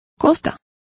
Complete with pronunciation of the translation of coasts.